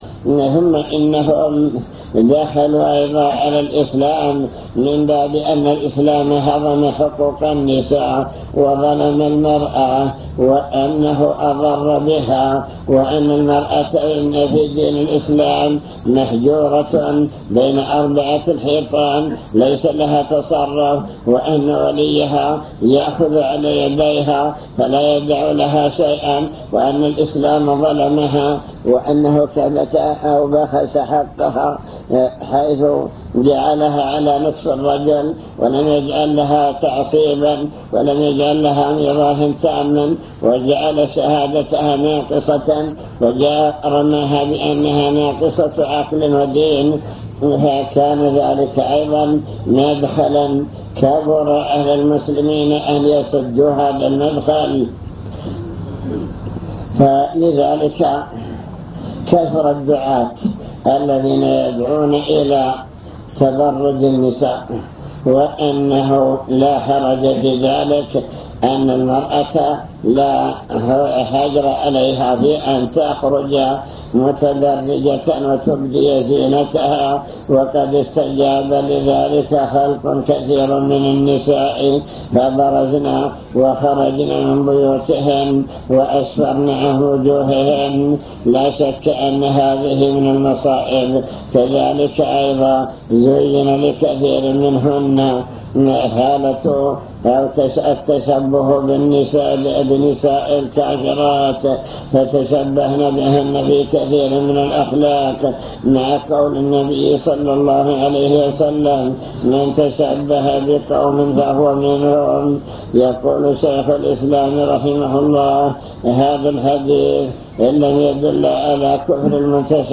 المكتبة الصوتية  تسجيلات - محاضرات ودروس  محاضرات في محافظة الباحة مكائد أعداء الإسلام